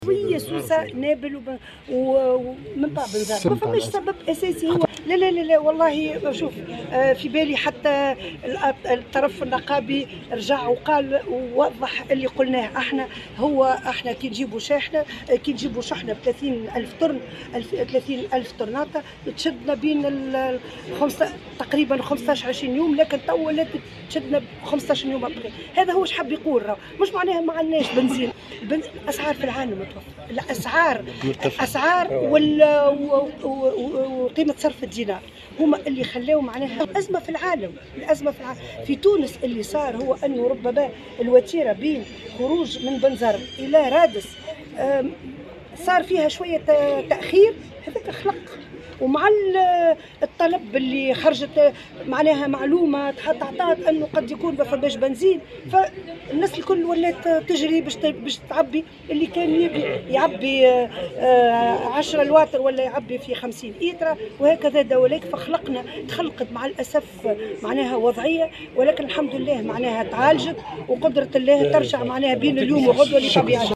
وأكدت الوزيرة في نقطة إعلامية انتظمت بمقر مصنع عجين الحلفاء والورق بالقصرين أنه لا توجد في تونس أزمة في توفر المحروقات بل الازمة توجد في العالم وهي متعلقة بارتفاع الأسعار وقيمة صرف الدينار، موضحة أن ما حصل في البلاد وتسبب في نقص المحروقات هو حدوث تأخير بعض الشيء في خروج الحمولات من ميناء بنزرت الى ميناء رادس رافقه تداول معلومات حول إمكانية نفاد المحروقات من البلاد وهو ما تسبب في ارتفاع الطلب وتقلص العرض.